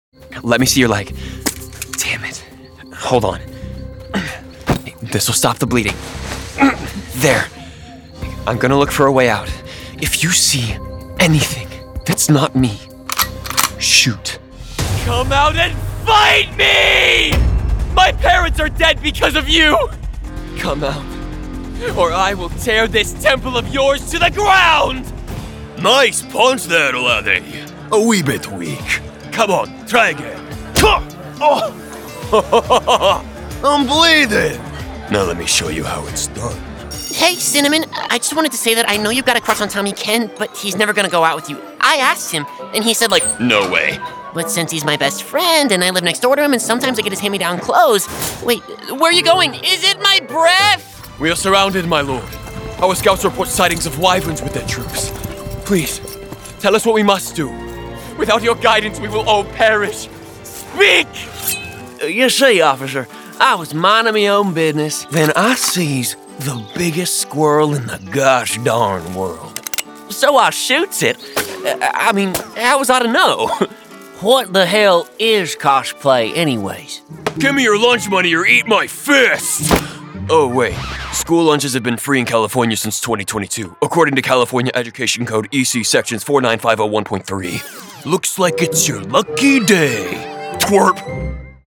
VO / Animation